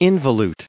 1779_involute.ogg